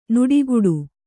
♪ nuḍiguḍu